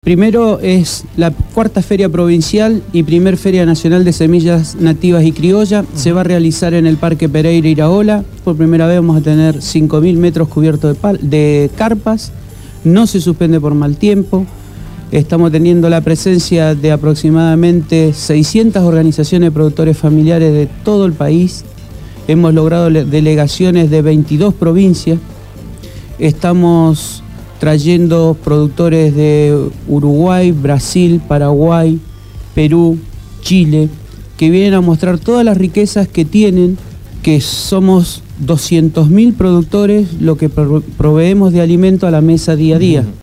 estuvo en el estudio de la Gráfica.
Entrevistado